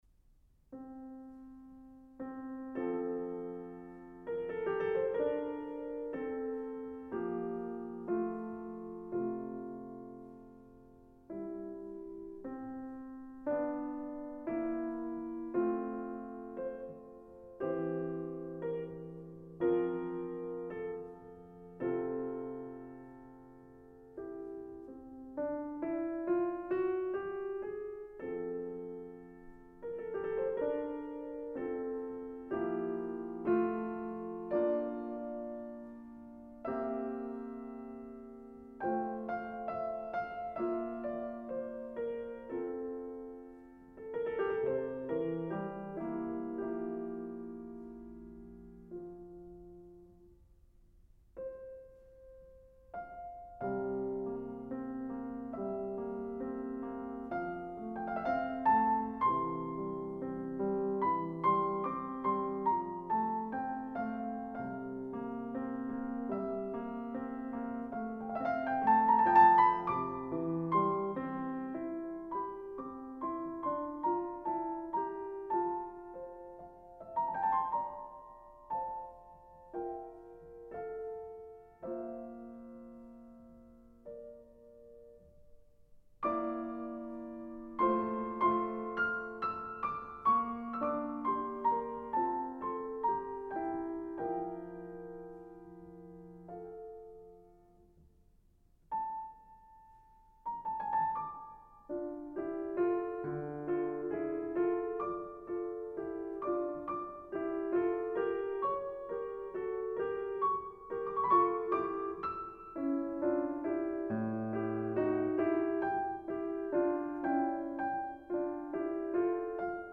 Adagio